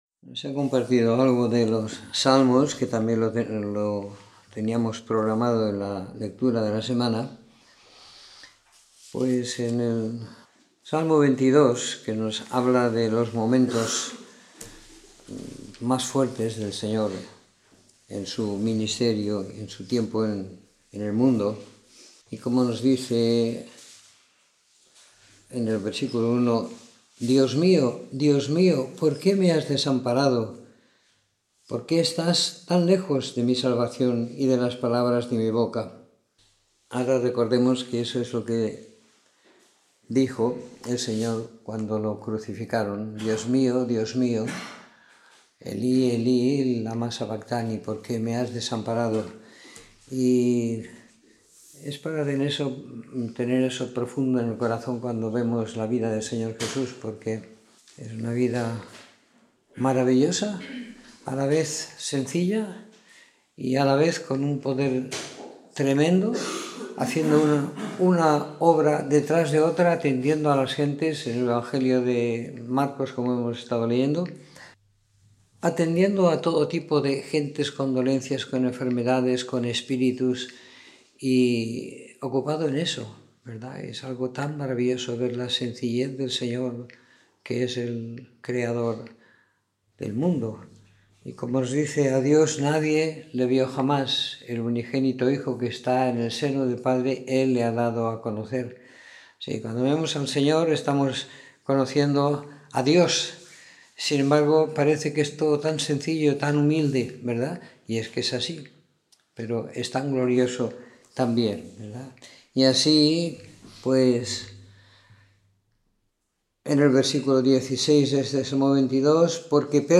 Comentario en el evangelio de Marcos siguiendo la lectura programada para cada semana del año que tenemos en la congregación en Sant Pere de Ribes.